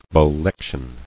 [bo·lec·tion]